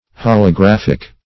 Holographic \Hol`o*graph"ic\, a.